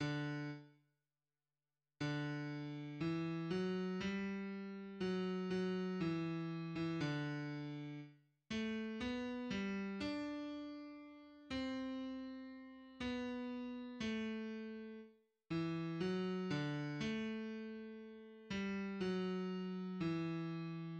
{\clef bass \key d \major \tempo 4=120 d4 r2 r4 d2 e4 fis g2 fis4 fis e4. e8 d2 r4 a b g d'2. c' b2 a r4 e fis d a2. g4 fis2 e }\midi{}